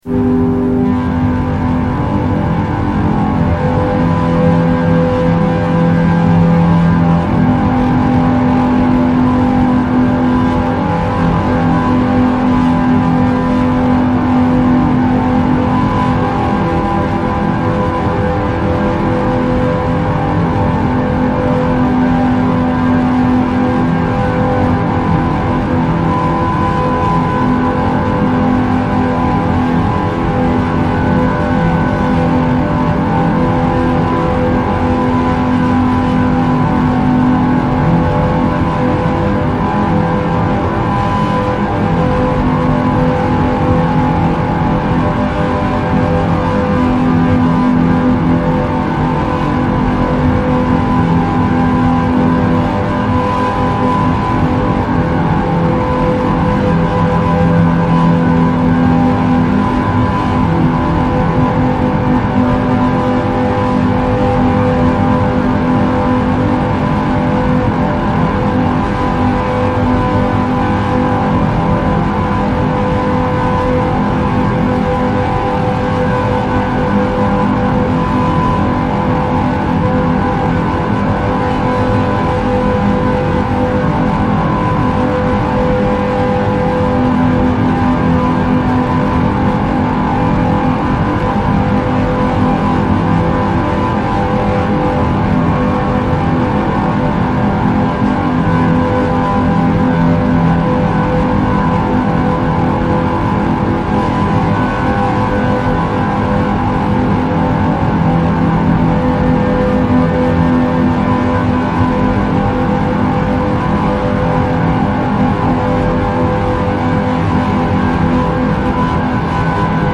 with MB-like crumble and haunted tunneling.